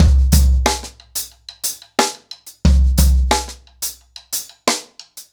InDaHouse-90BPM.31.wav